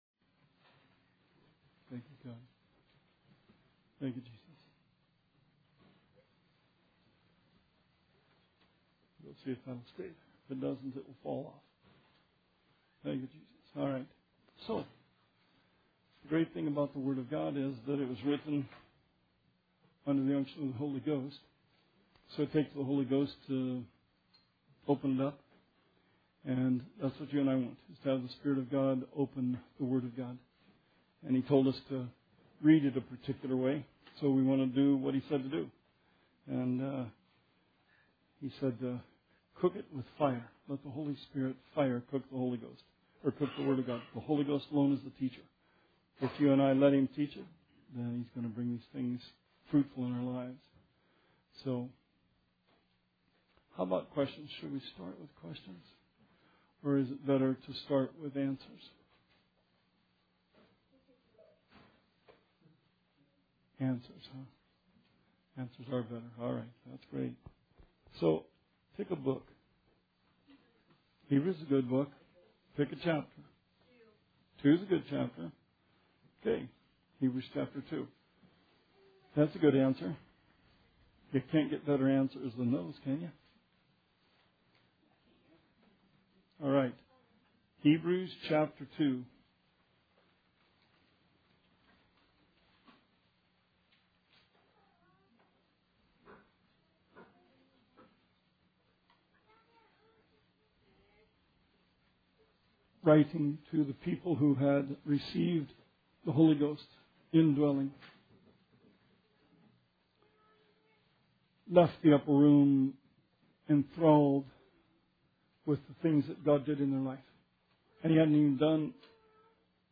Bible Study 1/18/17